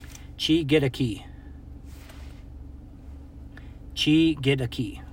Chigidaaki-Pronunciation.m4a